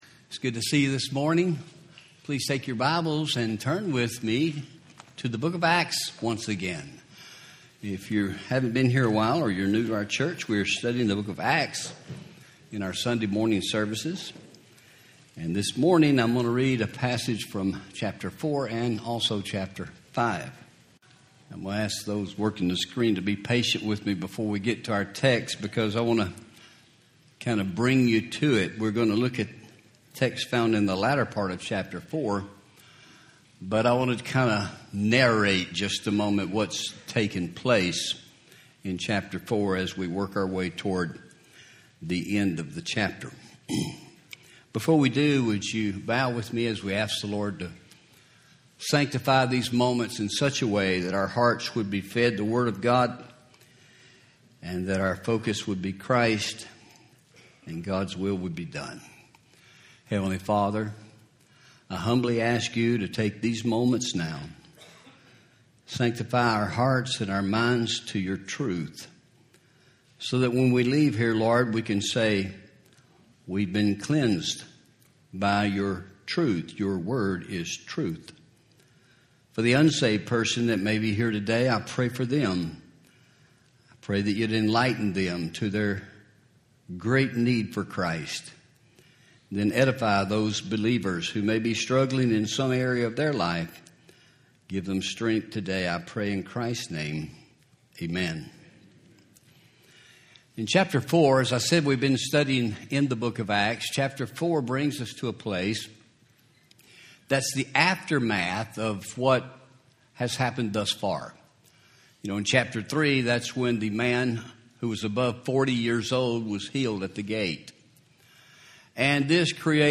Home › Sermons › Sin In The Church